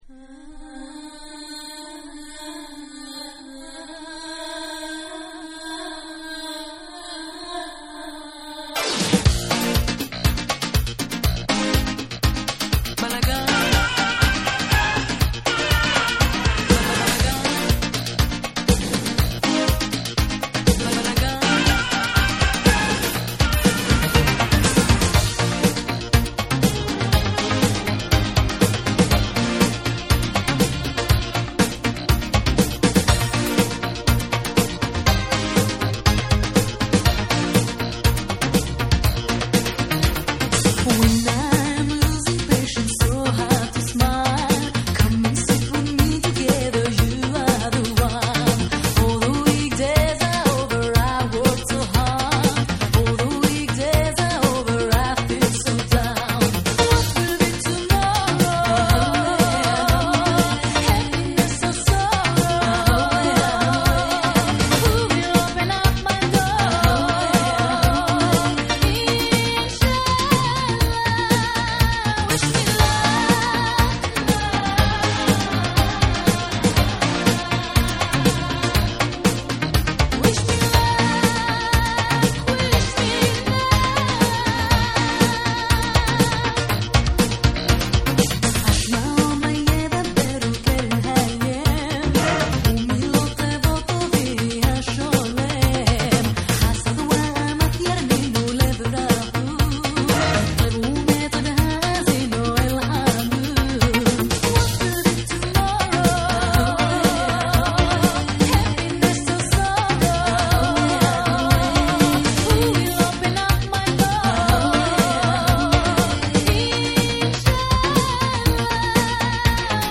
イスラエルのシンガー
イタロな要素を取り入れたダンサブルなトラックにオリエンタルなフレーズを散りばめ展開する
WORLD